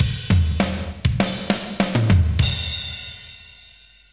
You can now be a one man band, keys marked with a "G" are electric guitar riffs, keys marked with a "B" are bass riffs, keys marked with a "D" are drum solos, mix and match and ROCK OUT!